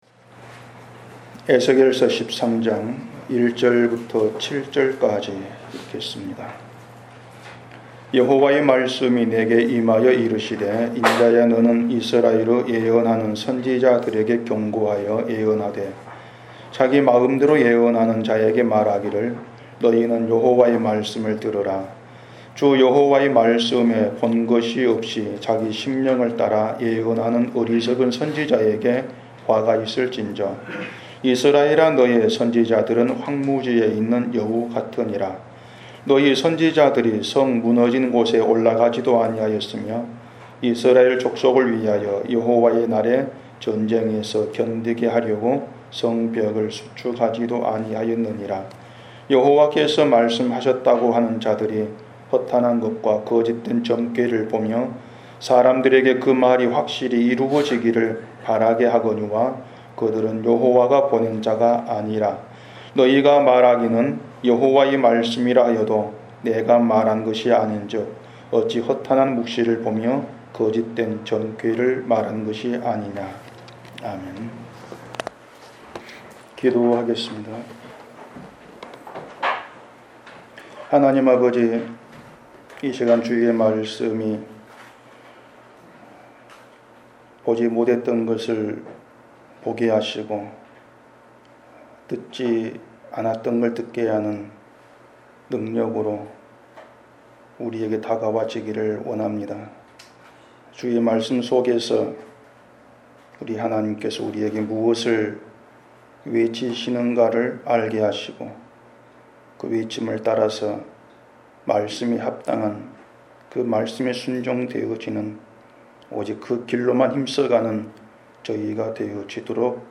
너희가 말하기는 여호와의 말씀이라 하여도 내가 말한 것이 아닌즉 어찌 허탄한 묵시를 보며 거짓된 점괘를 말한 것이 아니냐 <설교> 지난 주일에 신자를 역사가 아니라 묵시를 사는 사람으로 말씀드렸습니다 .